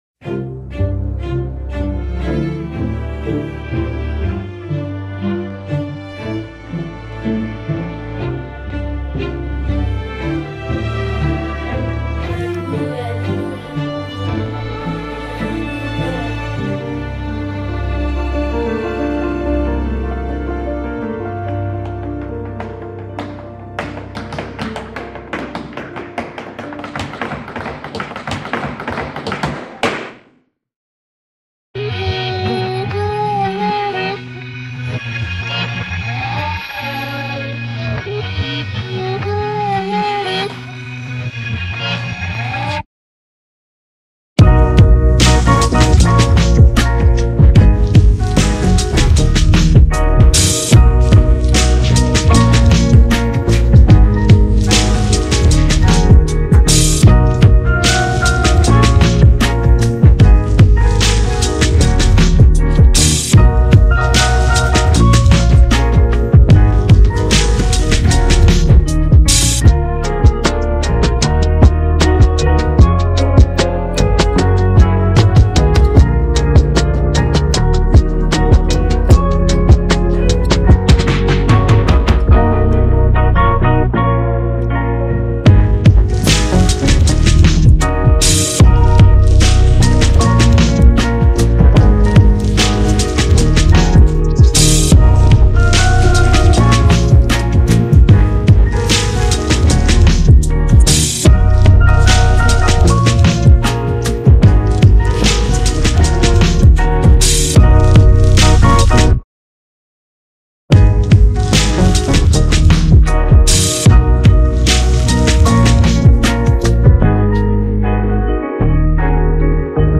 Listen and download the new free hip hop instrumental